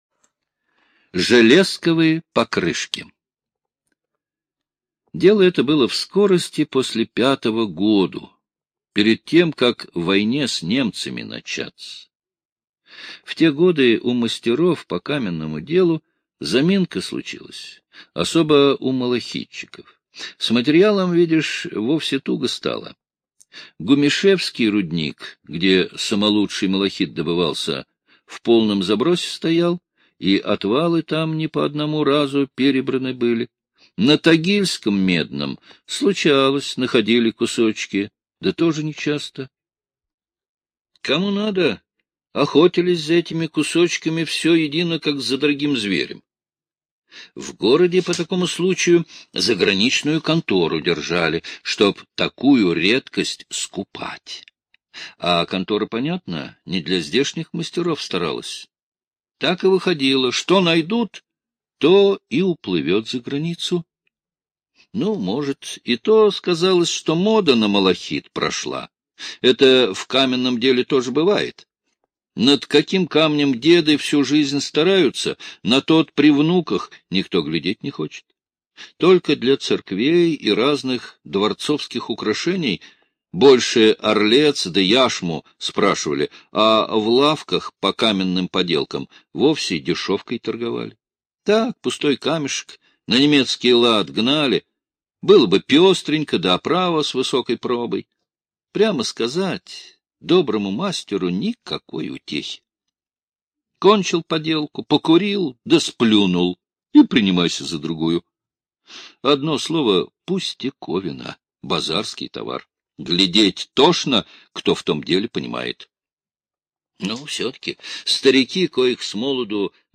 Железковы покрышки - аудиосказка Павла Бажова - слушать онлайн